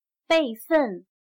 备份/Bèifèn/Pieza de recambio, extra.